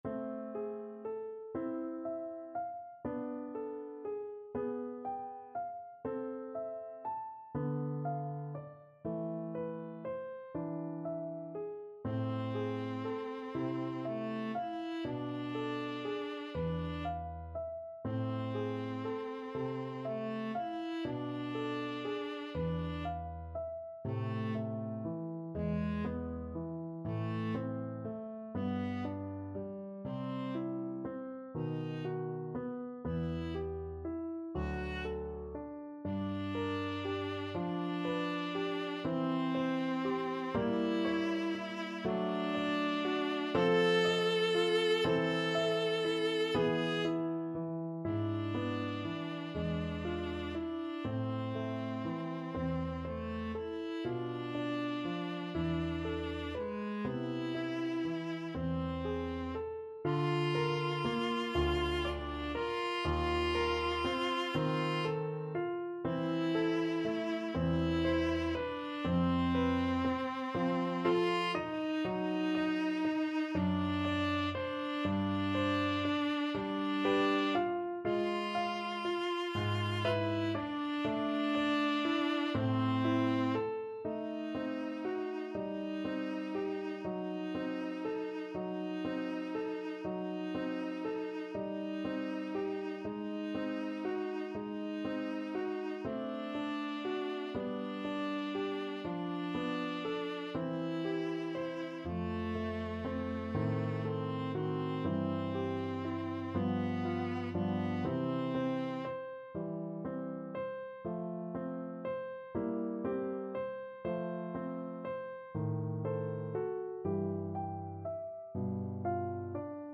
12/8 (View more 12/8 Music)
= 120 Larghetto